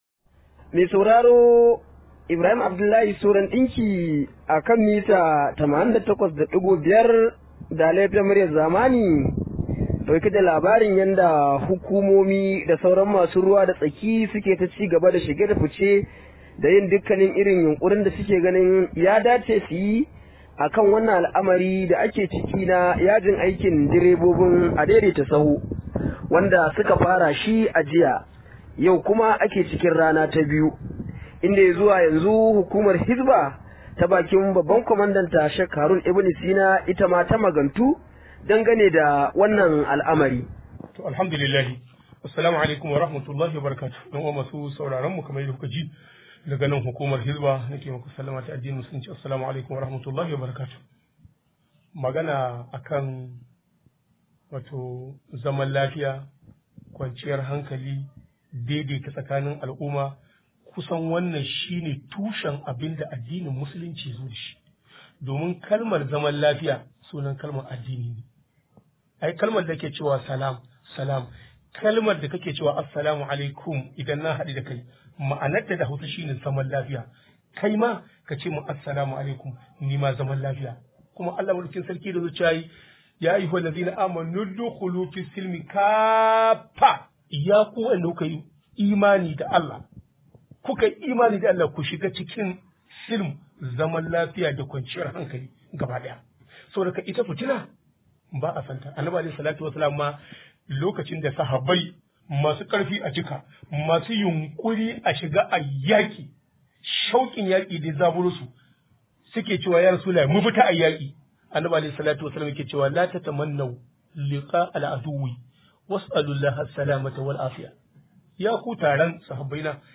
Rahoto: Kada matasa su yarda a yi amfani da su domin tayar da fitina